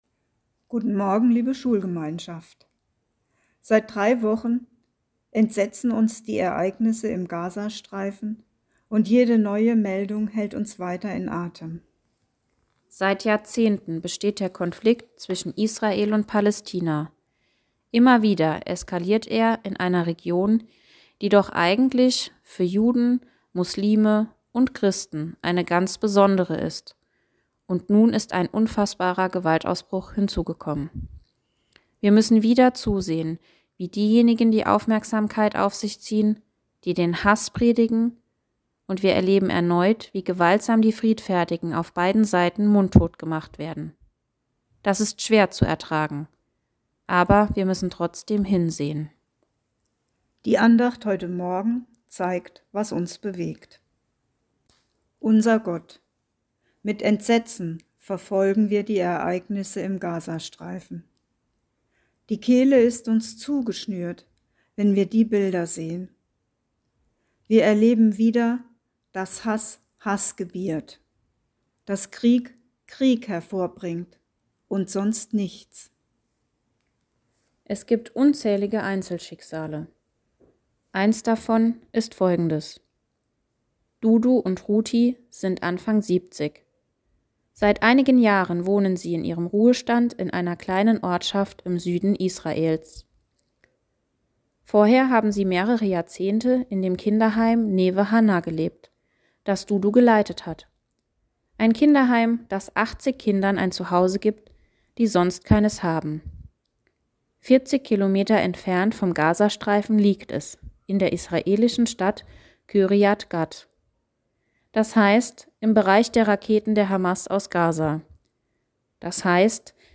Neue Audioandacht vom ersten Schultag nach den Herbstferien